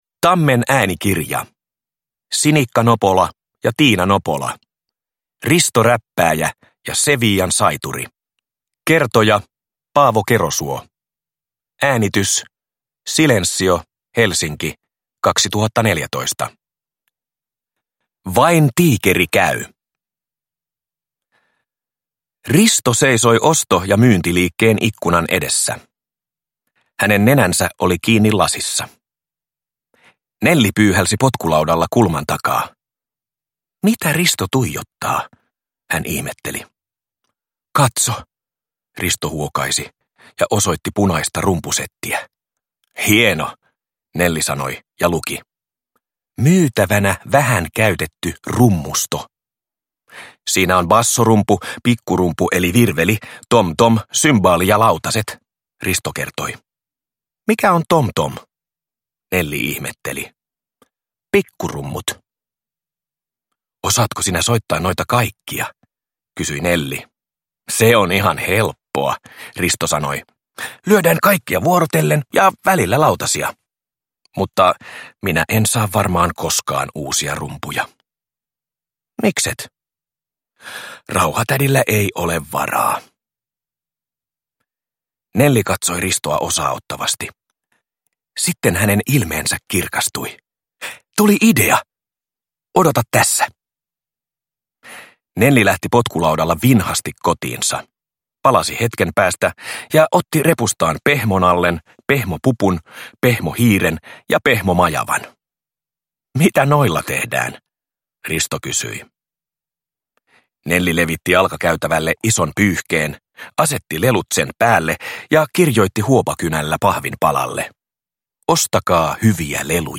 Risto Räppääjä ja Sevillan saituri – Ljudbok